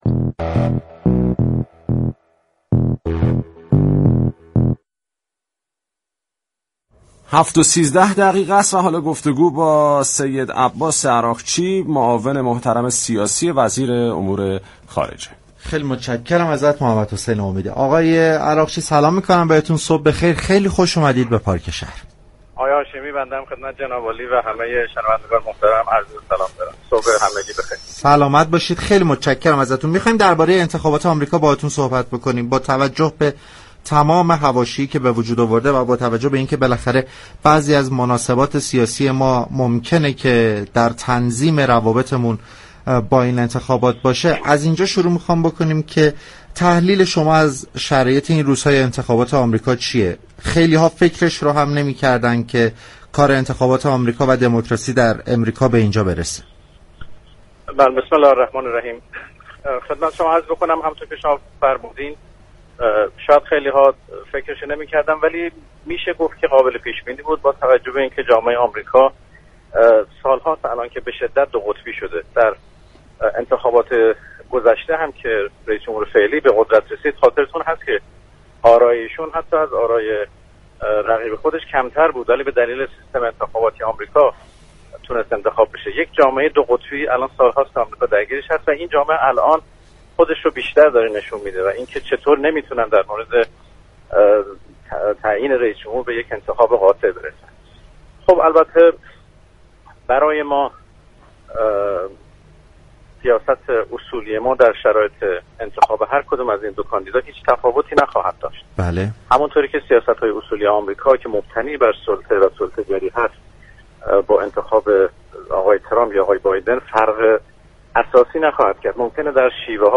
دریافت فایل به گزارش پایگاه اطلاع رسانی رادیو تهران، سیدعباس عراقچی در گفتگو با برنامه پارك شهر رادیو تهران درباره انتخابات ریاست جمهوری آمریكا گفت: چند سالیست آمریكا دچار یك جامعه به شدت دوقطبی شده به همین دلیل اتفاقات اخیر در انتخابات قابل پیش بینی بود.